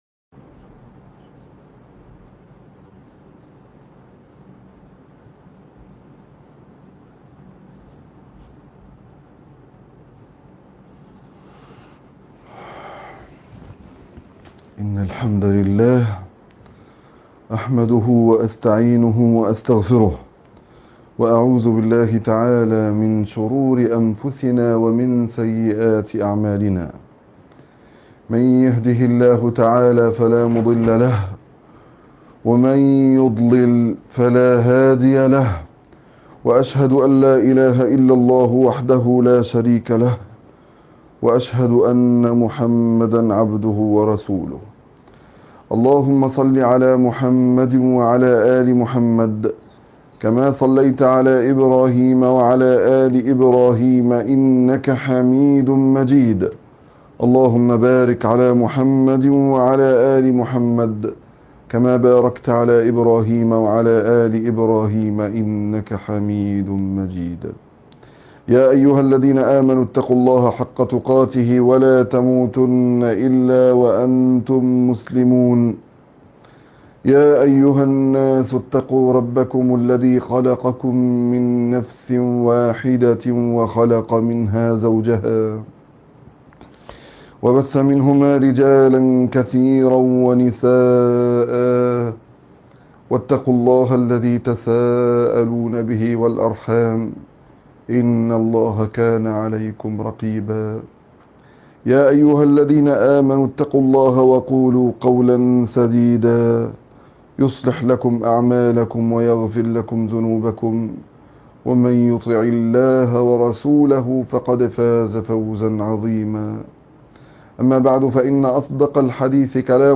خطبة الجمعة الأخيرة رمضان ١٤٤١